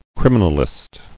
[crim·i·nal·ist]